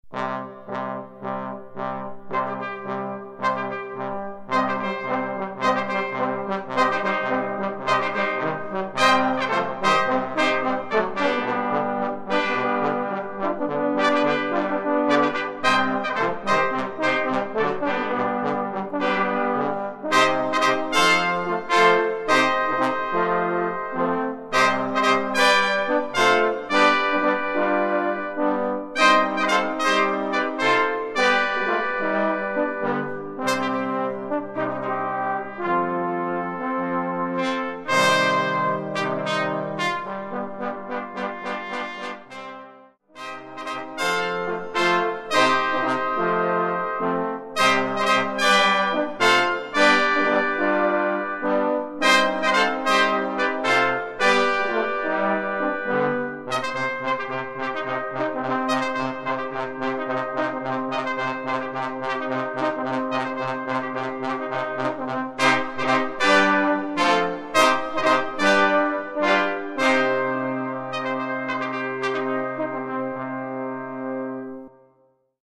Unterkategorie 5 Blechbläser
Besetzung 5Brass (5 Blechbläser)
Zusatzinfo/Inhalt Perchtenfanfare